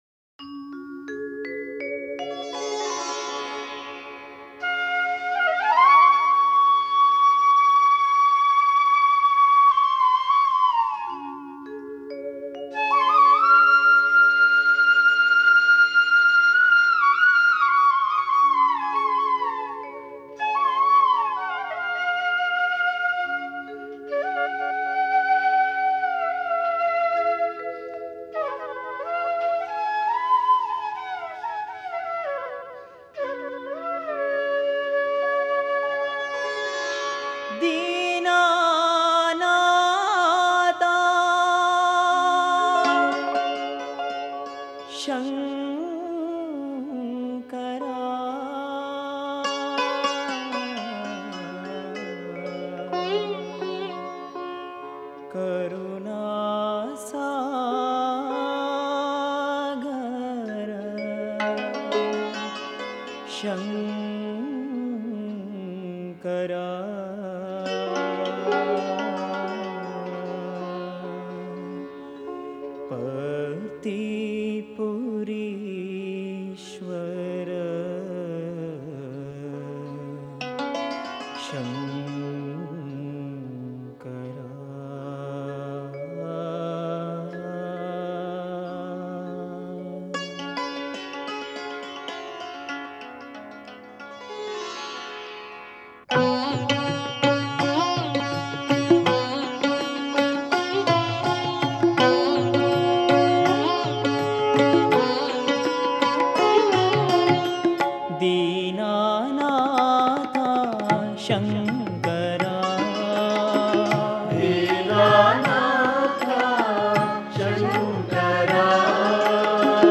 Author adminPosted on Categories Shiva Bhajans